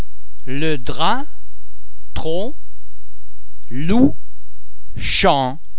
The word-final [p] is usually silent: